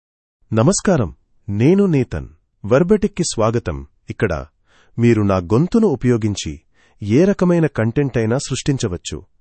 Nathan — Male Telugu AI voice
Nathan is a male AI voice for Telugu (India).
Voice sample
Listen to Nathan's male Telugu voice.
Nathan delivers clear pronunciation with authentic India Telugu intonation, making your content sound professionally produced.